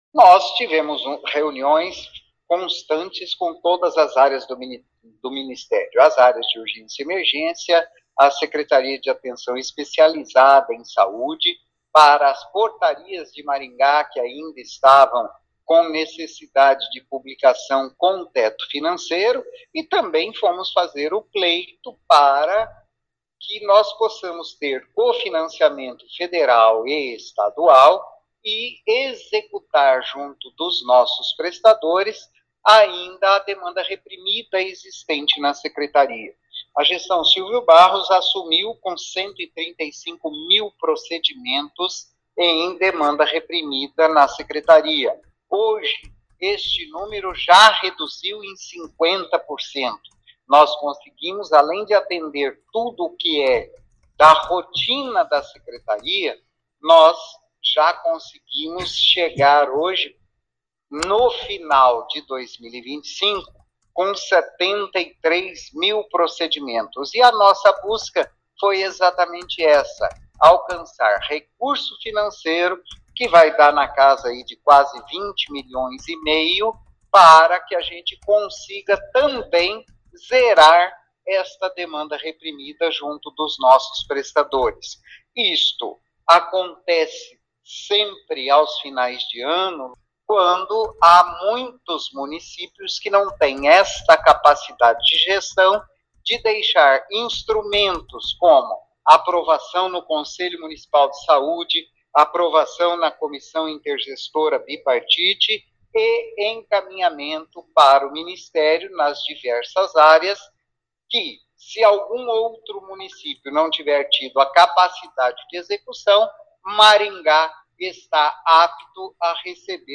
A Secretaria de Saúde espera conseguir R$ 20 milhões. Ouça o que diz o secretário: